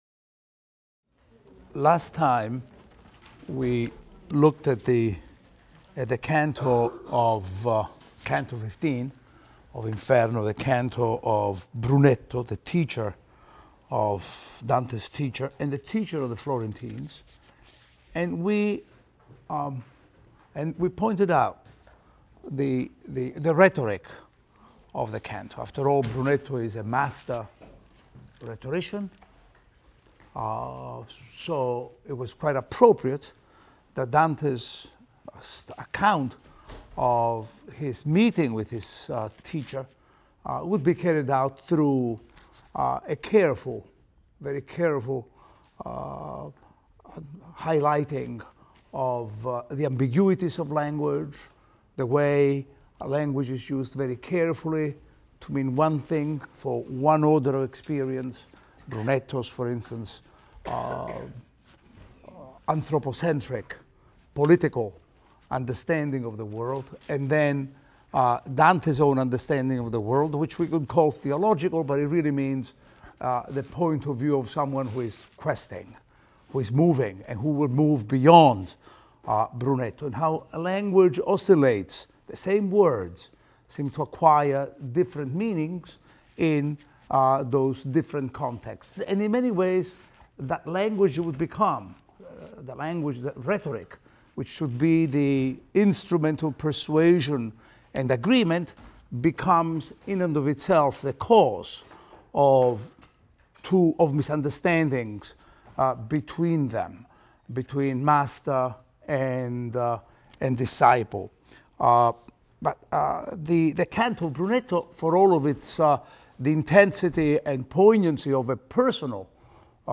ITAL 310 - Lecture 7 - Inferno XIX, XXI, XXV, XXVI | Open Yale Courses